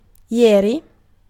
Ääntäminen
France (Paris): IPA: /(i).jɛʁ/